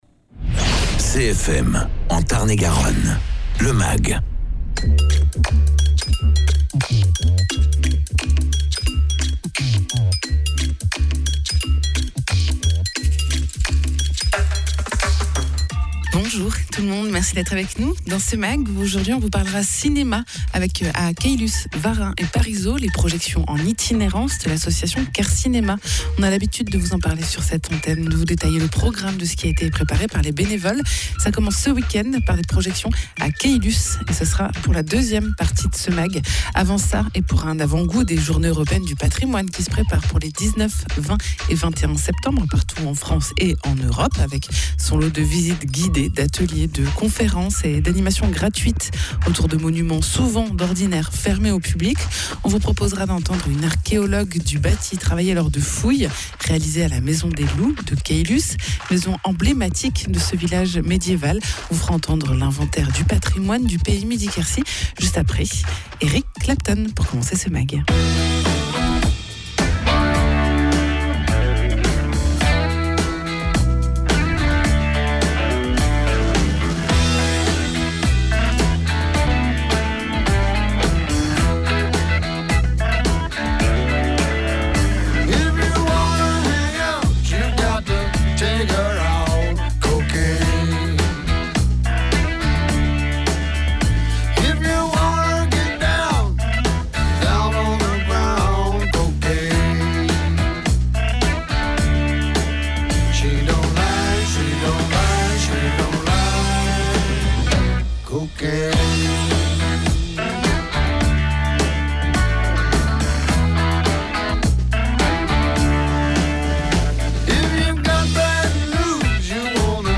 Les 4 associations qui forment Quercynéma proposent en cette rentrée tout un programme de films projetés en itinérance sur Caylus, Varen et Parisot : ça commence par Caylus ce week-end. Egalement dans ce mag et en prévision des journées européenne du patrimoine qui se préparent, un reportage lors de fouilles archéologiques réalisées à la maison des loups de Caylus.